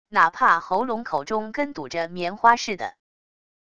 哪怕喉咙口中跟堵着棉花似的wav音频生成系统WAV Audio Player